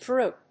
/drúːp(米国英語), dru:p(英国英語)/